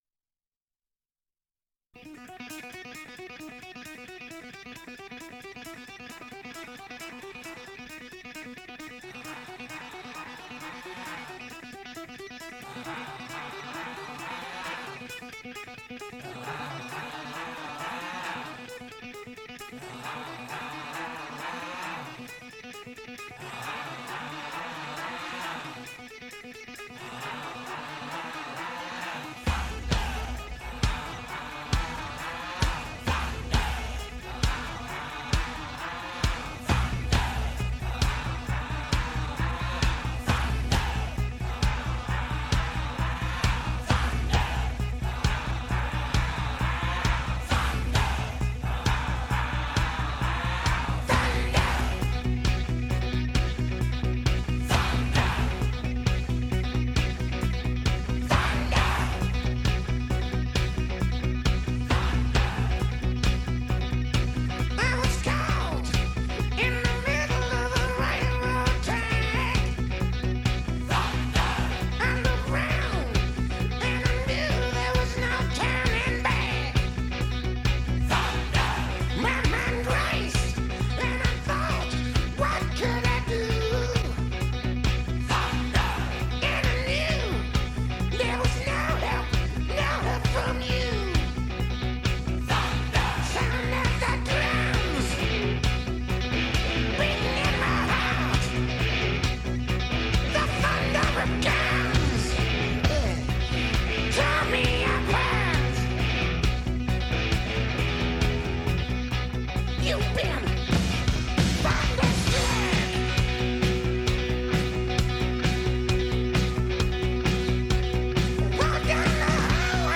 Here is a test recording made on the BX-125 on a normal position tape with Dolby B that is played back by it: